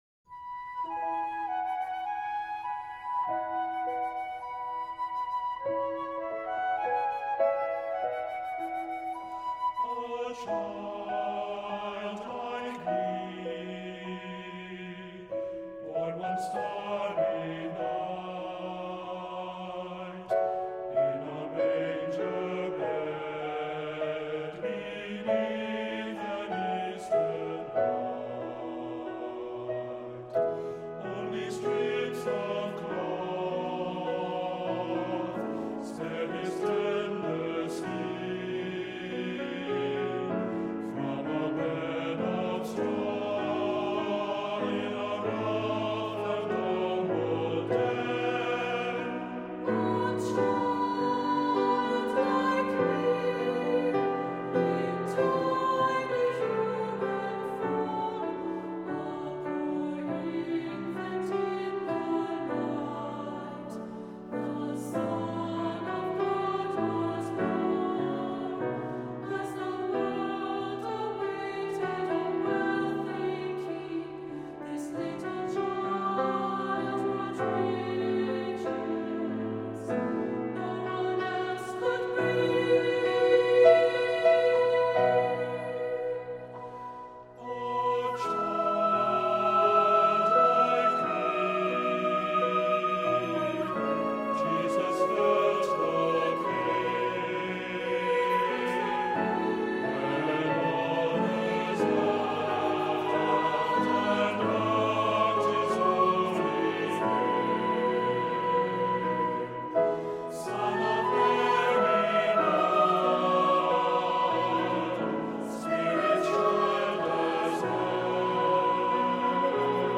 Accompaniment:      Keyboard, C Instrument
Music Category:      Choral
Descant and C instrument parts are optional.